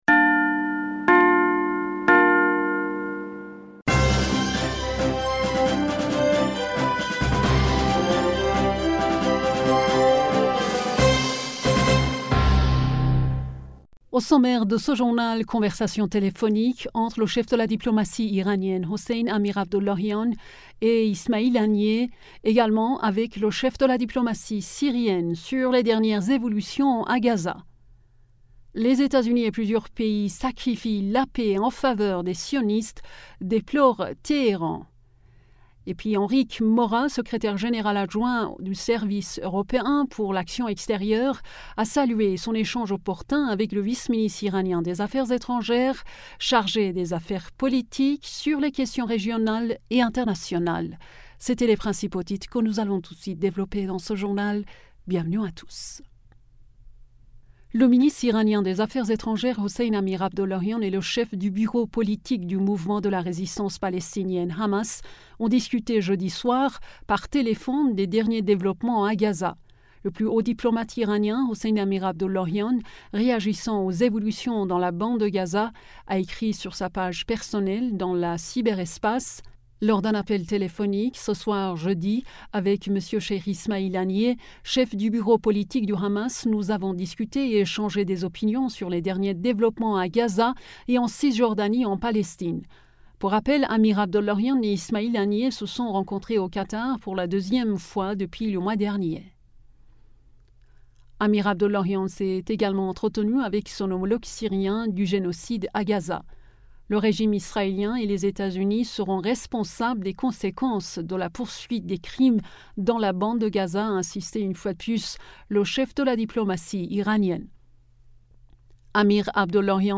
Bulletin d'information du 03 Novembre 2023